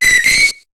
Cri de Rémoraid dans Pokémon HOME.